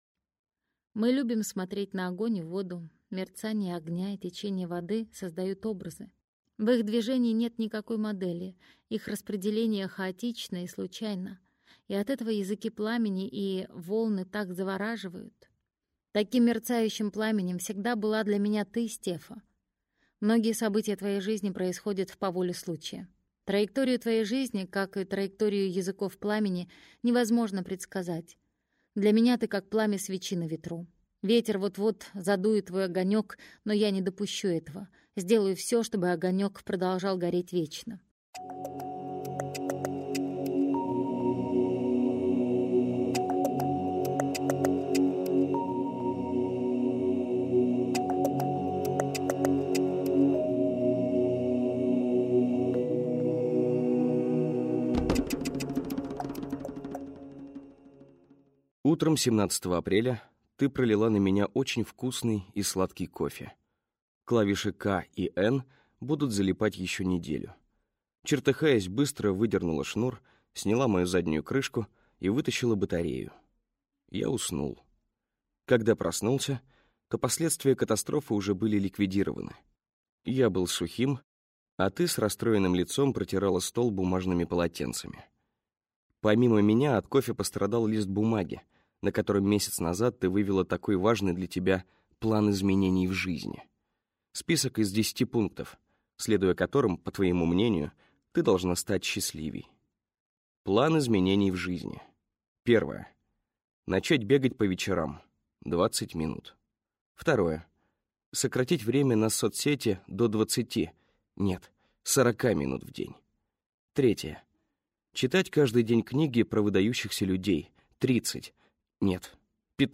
Аудиокнига Везувиан - купить, скачать и слушать онлайн | КнигоПоиск